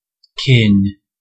Ääntäminen
Synonyymit people relative brood Ääntäminen US Tuntematon aksentti: IPA : /kɪn/ Haettu sana löytyi näillä lähdekielillä: englanti Määritelmät Substantiivit Race ; family ; breed ; kind .